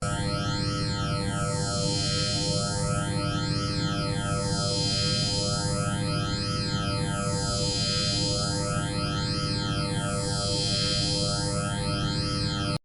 Transformer Phases
SFX
Transformer Phases.mp3